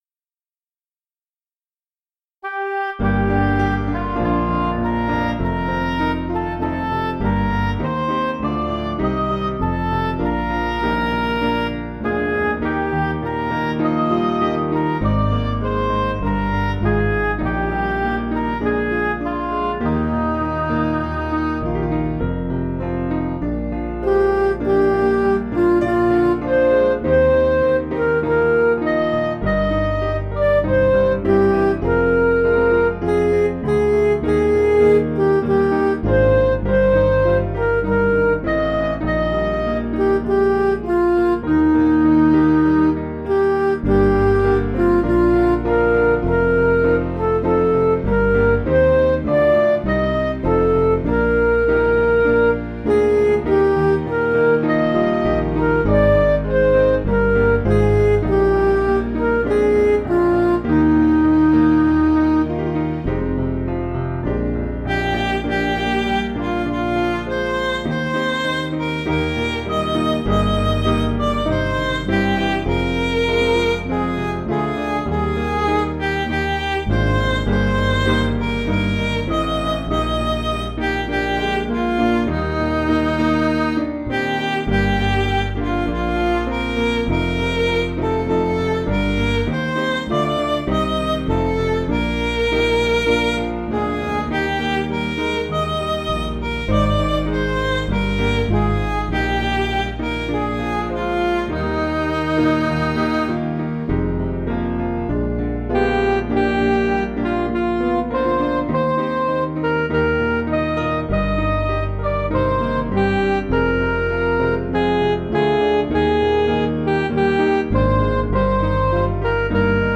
Piano & Instrumental
(CM)   7/Eb
Midi